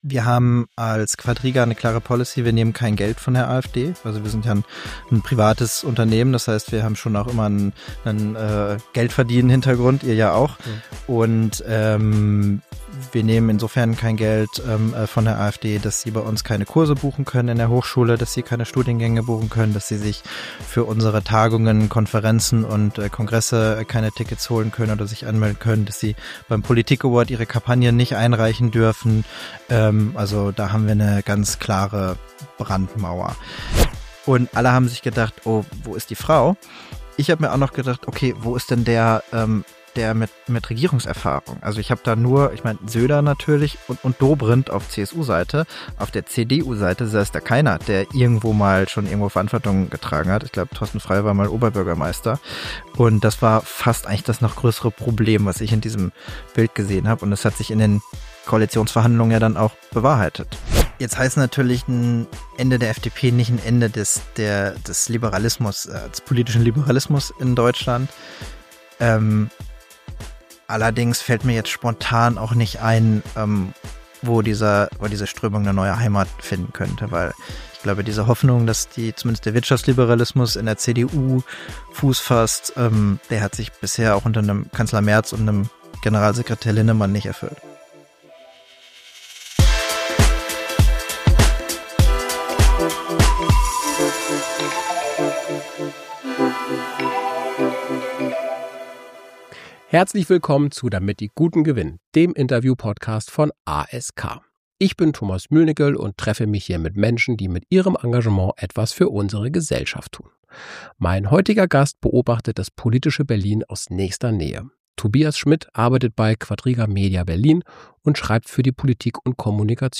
Ein Gespräch über die Frage, was gute Kommunikation für die Demokratie tun kann.